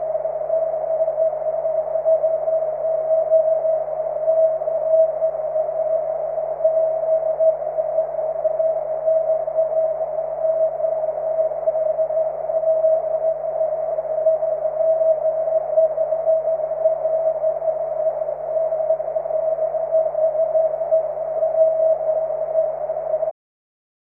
All contacts are on CW unless otherwise stated.